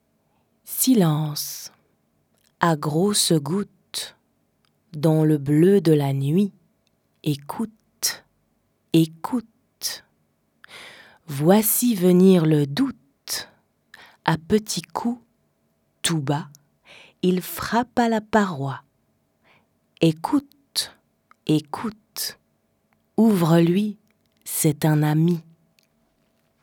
SILENCE VOIX DE FEMME (RNS10)
L1_34_P_poeme_RNS10_silence_femme.mp3